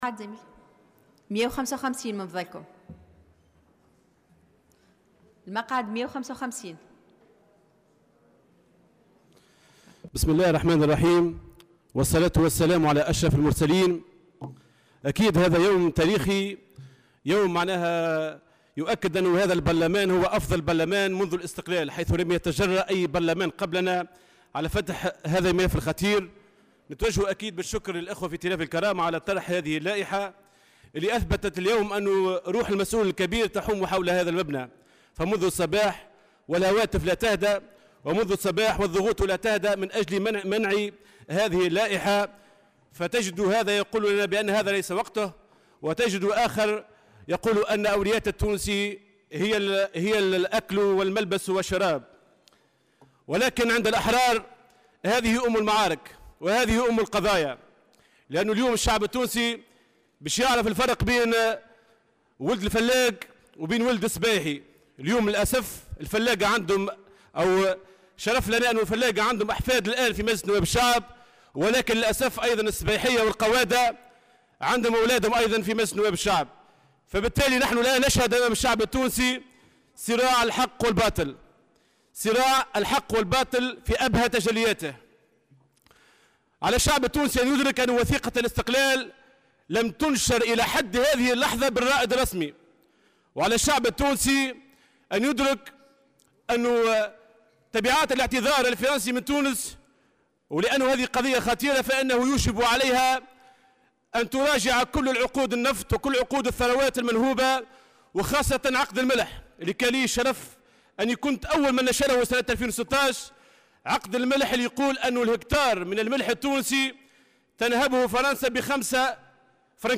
تعمّد النائب راشد الخياري تشويه صورة الزعيم الحبيب بورقيبة ورمزيته في مداخلة أثناء جلسة عامة تتعلّق بلائحة تقدم سيف الدين مخلوف للمطالبة بالاعتذار من فرنسا.